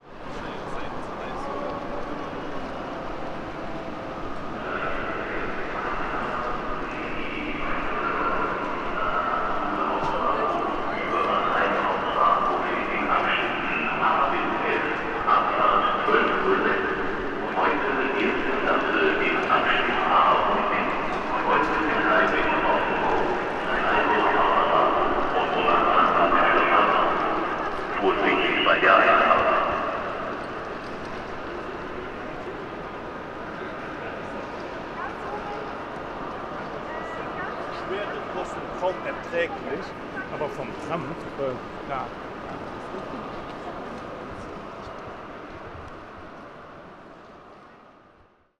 Stuttgart, Bahnhof - Nagra Lino
gare_S21.mp3